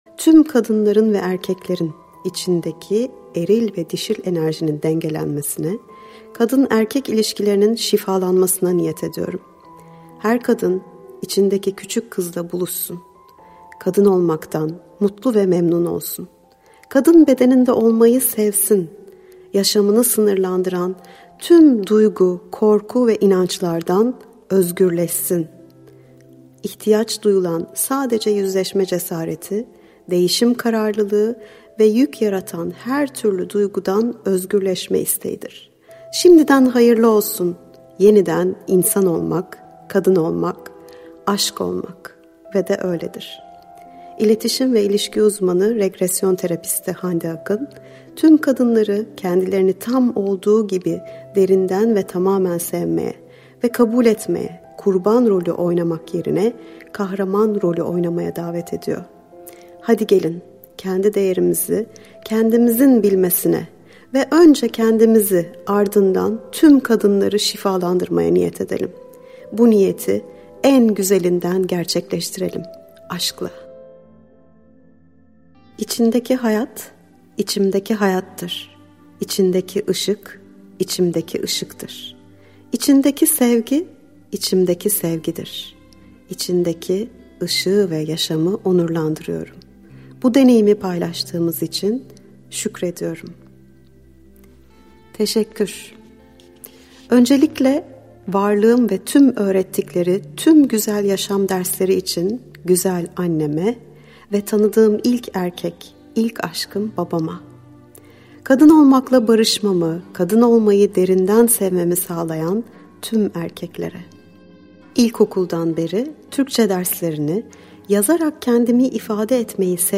Kadın Olmak - Seslenen Kitap